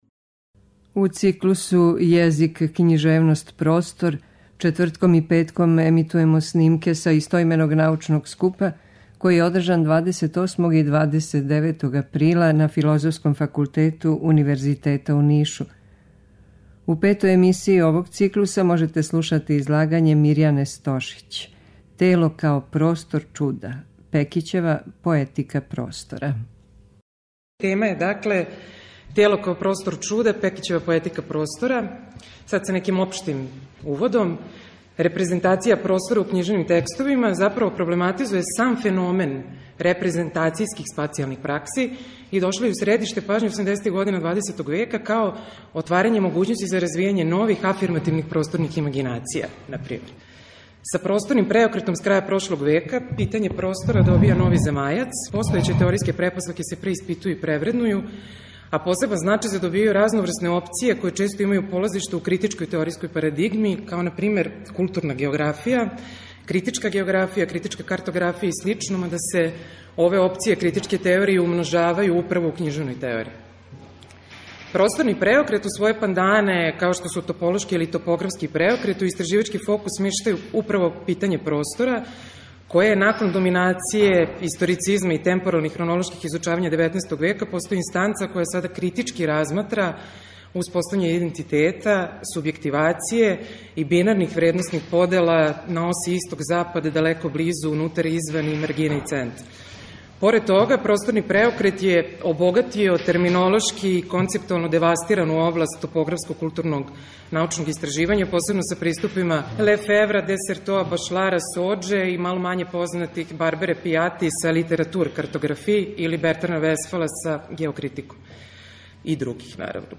У циклусу ЈЕЗИК, КЊИЖЕВНОСТ, ПРОСТОР четвртком и петком ћемо емитовати снимке са истименог научног скупа, који је ордржан 28. и 29. априла на Филозофском факултету Универзитета у Нишу.
Научни скупoви